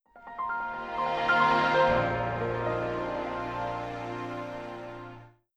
Windows 2000 & ME Startup (Remastered).wav